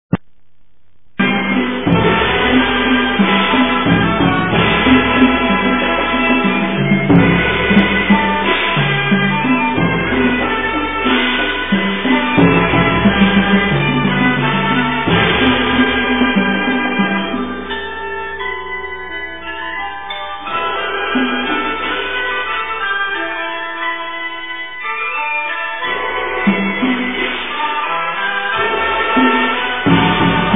Ensemble of Chinese Wind & Percussion Instruments | Chinese Music | Folk
This collection of 8 traditional Chinese pieces, played on flute and drum, are great for any celebration.